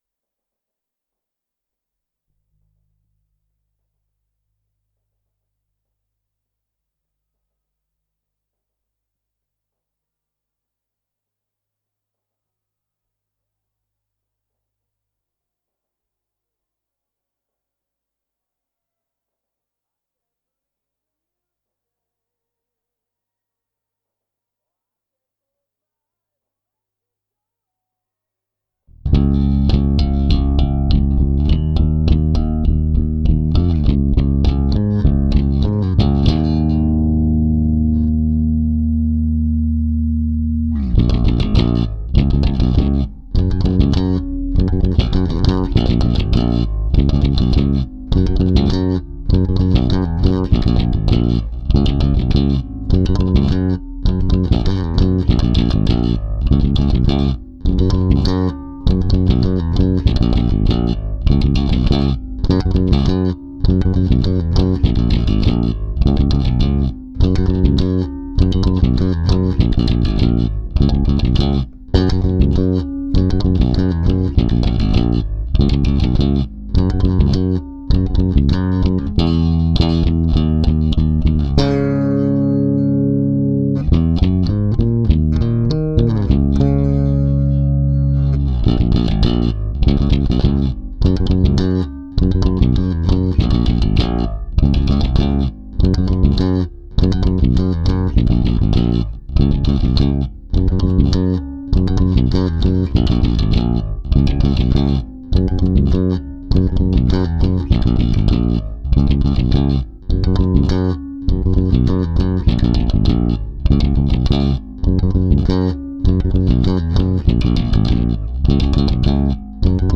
basse uniquement